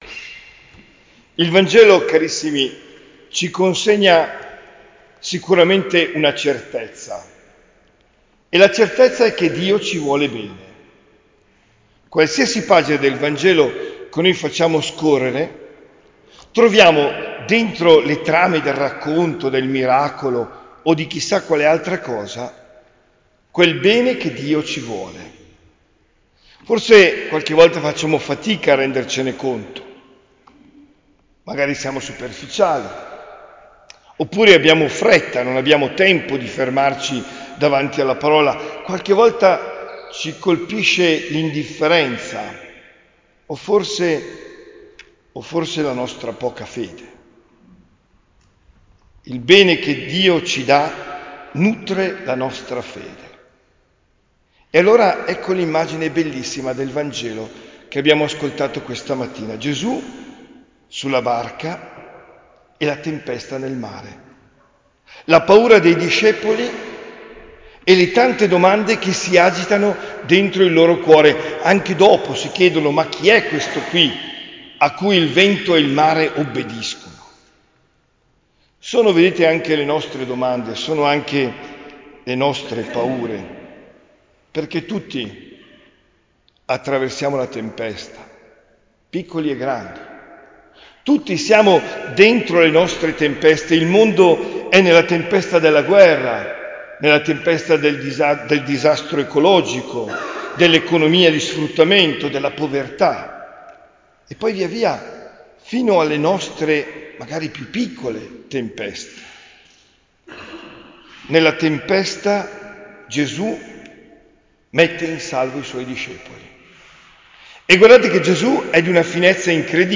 OMELIA DEL 23 GIUGNO 2024